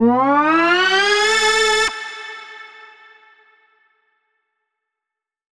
Index of /musicradar/future-rave-samples/Siren-Horn Type Hits/Ramp Up
FR_SirHornC[up]-A.wav